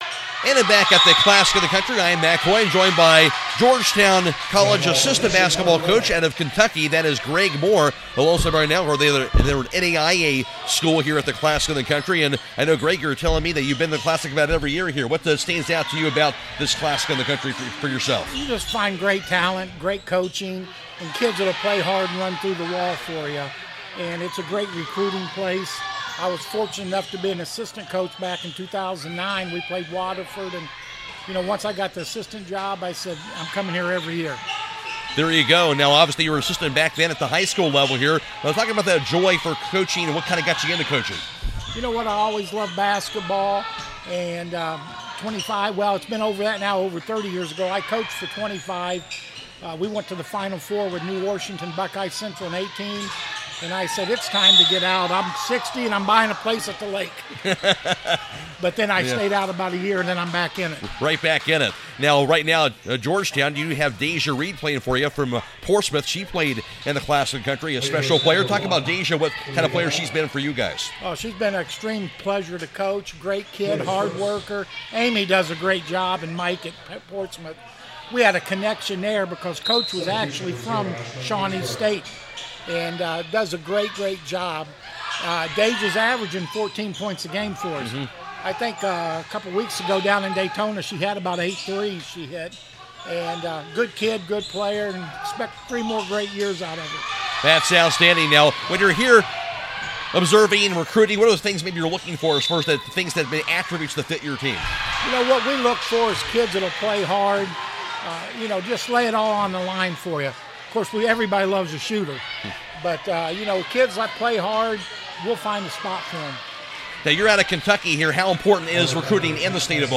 CLASSIC 2026 – GEORGETOWN COACHES INTERVIEW